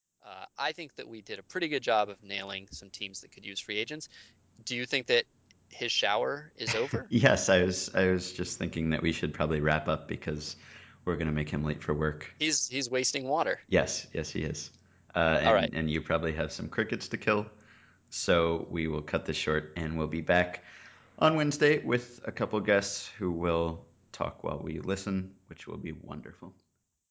Intro sound: A bear growls, a man urges “No, Ben, no!” and chaos ensues.